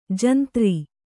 ♪ jantri